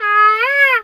bird_peacock_squawk_03.wav